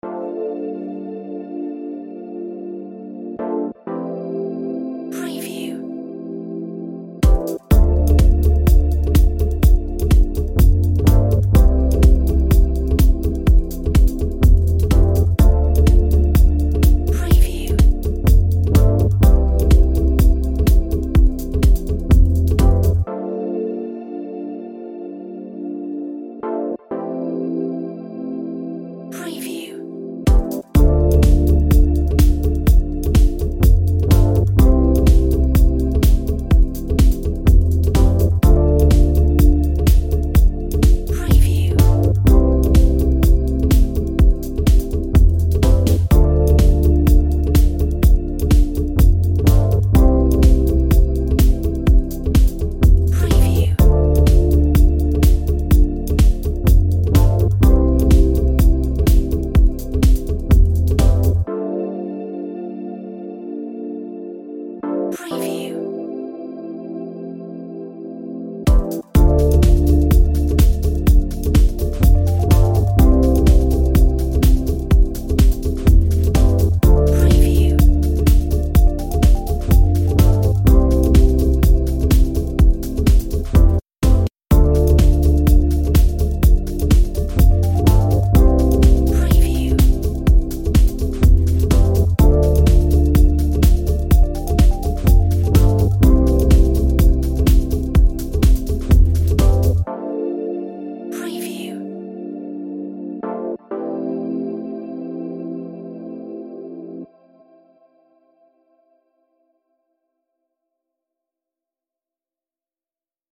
Funky chilled vibe